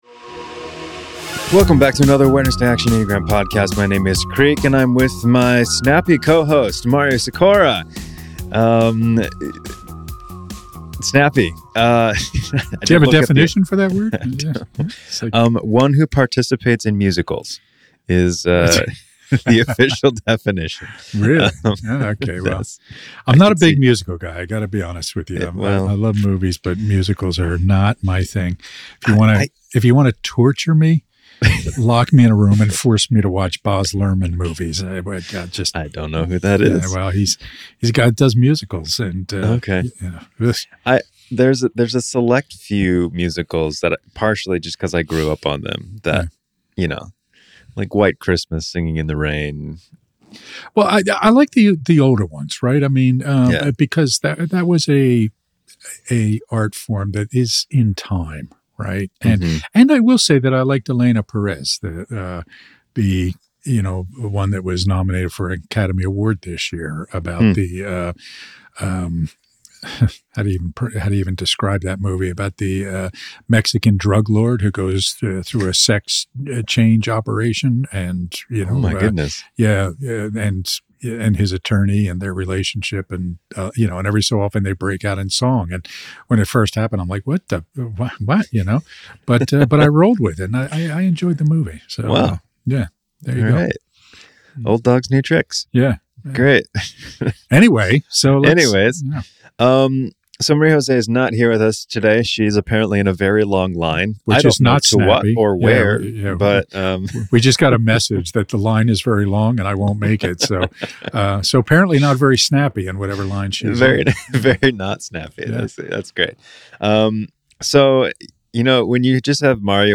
philosophical etymological conversation about three terms–descriptive, predictive and prescriptive–and how they can be misconstrued when it comes to the Enneagram of Personality.